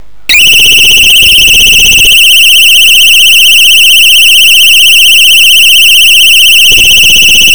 Microsirena con supporto. alimentazione da 6-14 Volts, 130mA (con 12Volts) Maxisuono da 100 db. Il suono emesso � "Effetto Allarme".